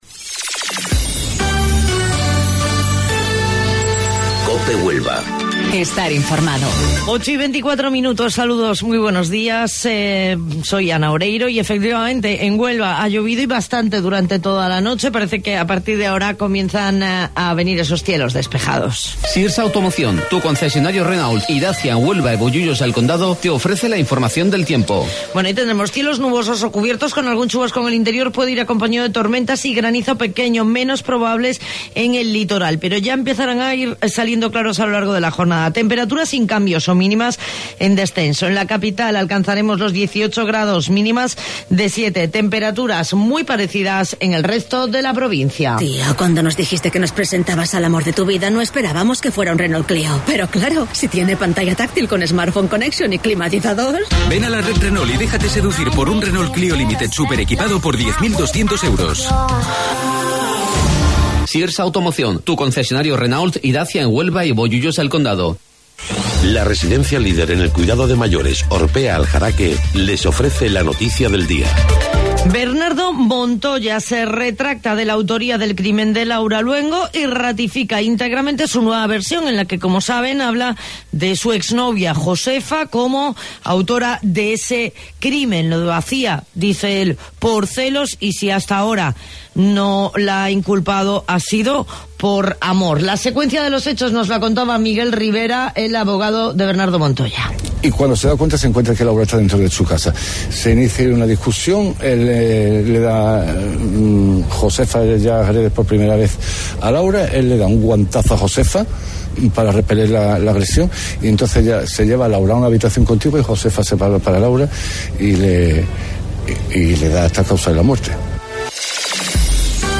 AUDIO: Informativo Local 08:25 del 5 de Abril